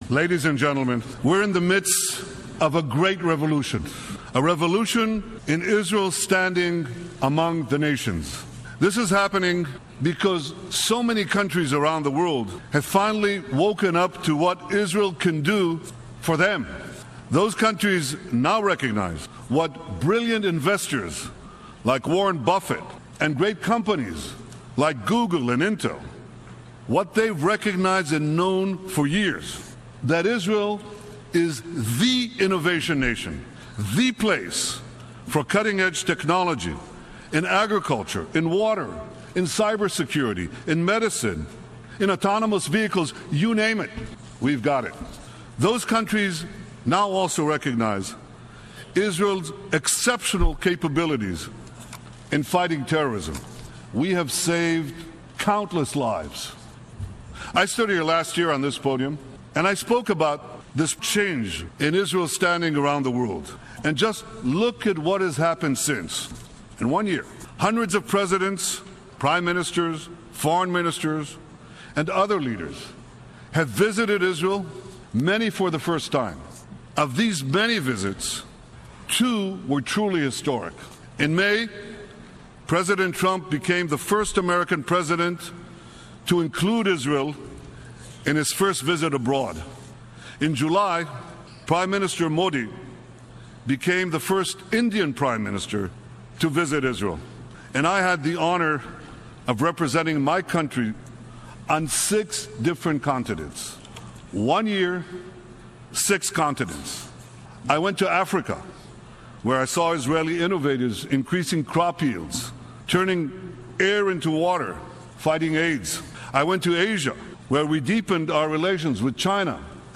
Israeli PM Benjamin Netanyahu's incredible & memorable speech at UN Sep 2017